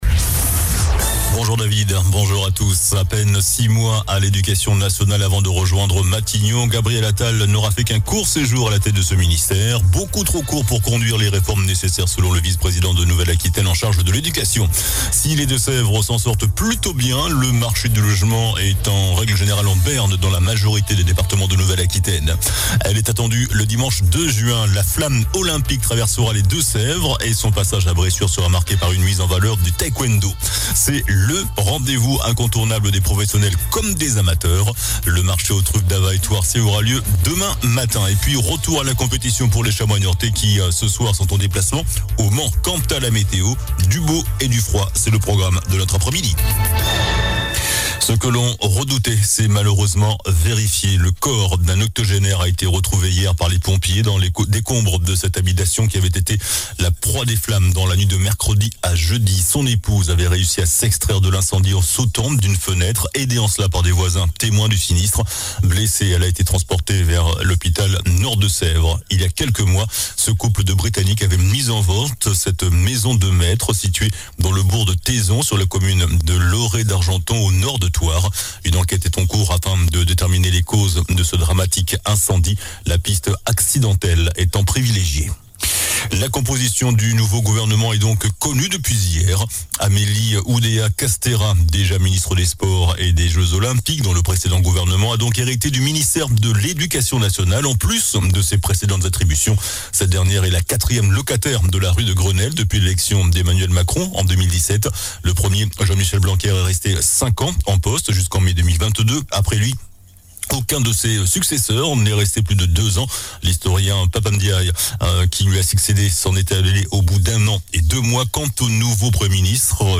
JOURNAL DU VENDREDI 12 JANVIER ( MIDI )